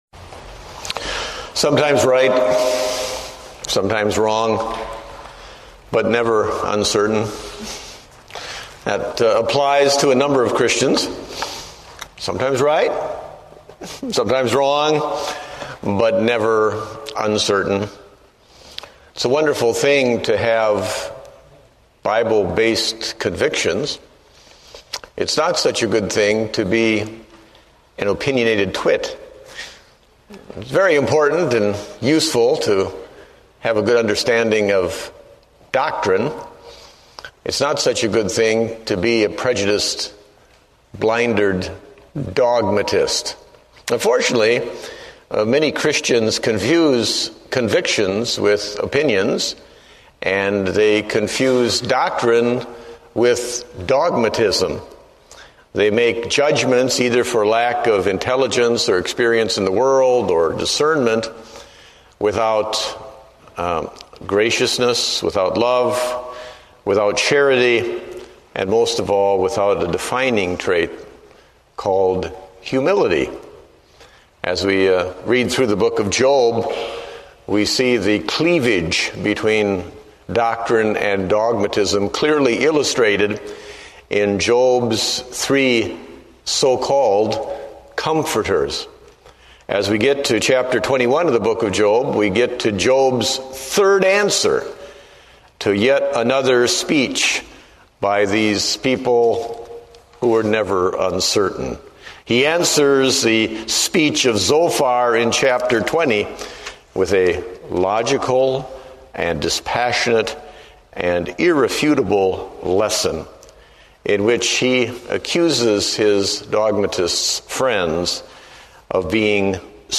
Date: July 6, 2008 (Evening Service)